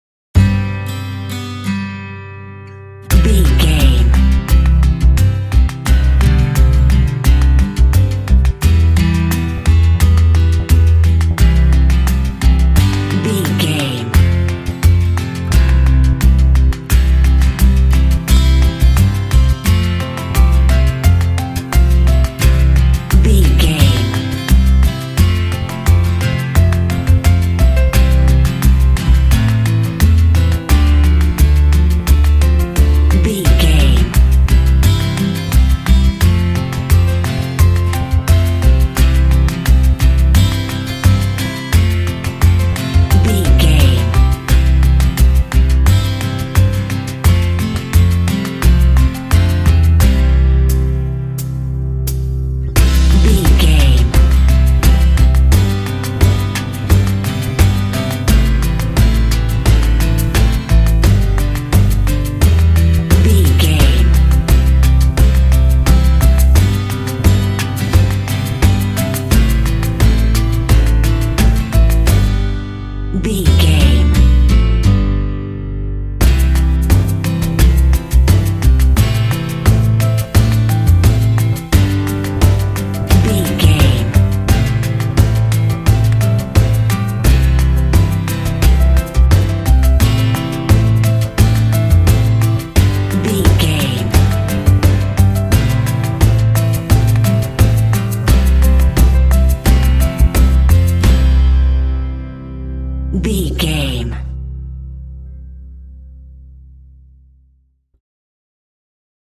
Ionian/Major
inspirational
hopeful
powerful
soothing
acoustic guitar
bass guitar
piano
percussion